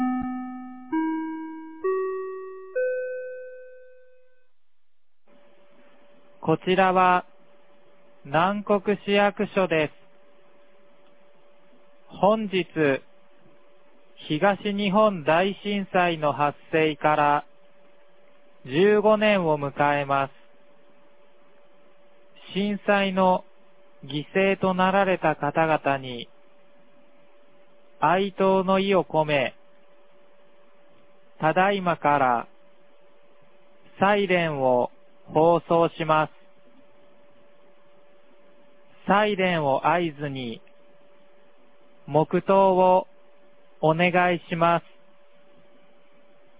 2026年03月11日 14時45分に、南国市より放送がありました。